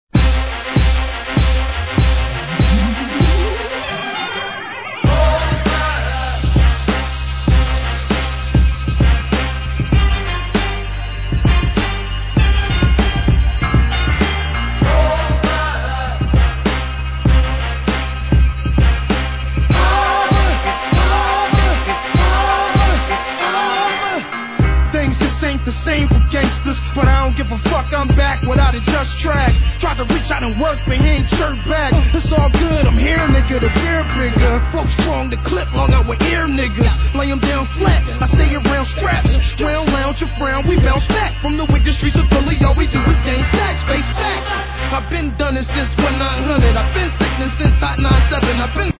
HIP HOP/R&B
キラキラした電子音の交錯する、R&Bファンも納得のプロダクションに